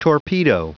Prononciation du mot torpedo en anglais (fichier audio)
Prononciation du mot : torpedo